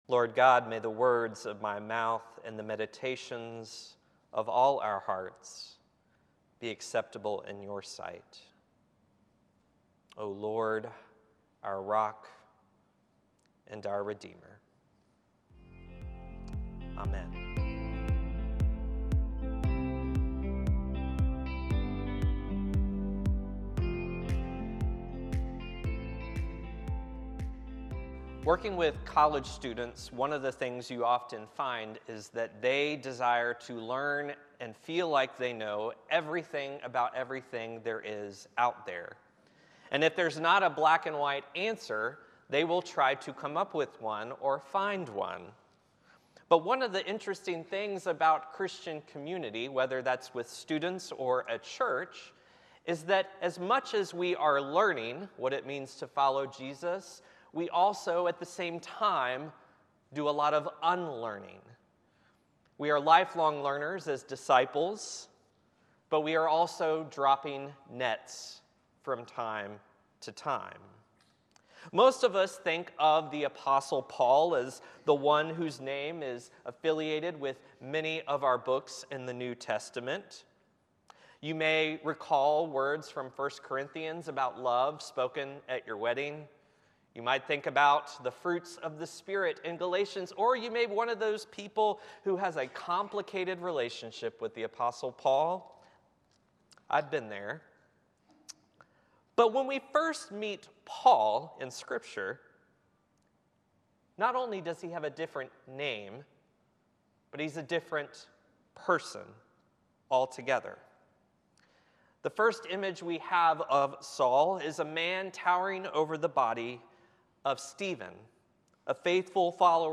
Through stories of Saul's encounter on the Damascus road and a modern neo-Nazi's redemption, the sermon reminds us that the church is called to embrace all people—even those who have harmed it—because everyone is created in God's image.